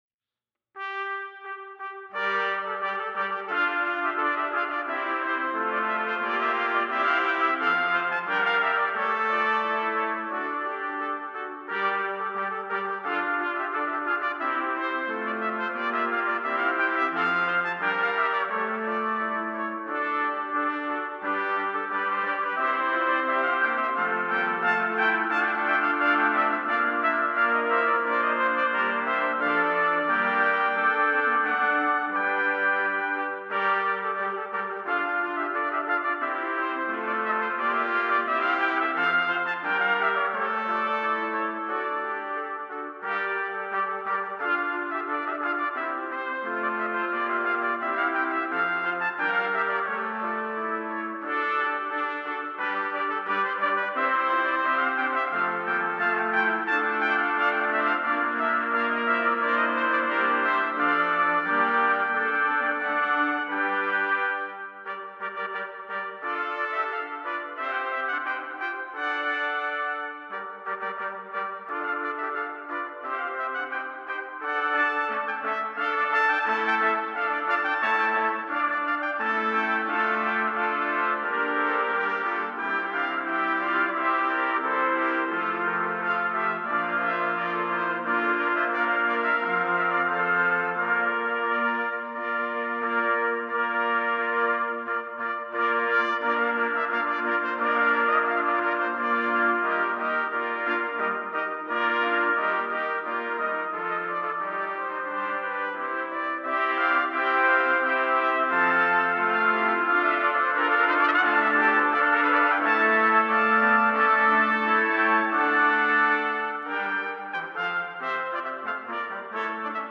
Number of Trumpets: 8
Key: d minor concert
for eight trumpets in B-flat
brings out the rich, sonorous textures and vibrant harmonies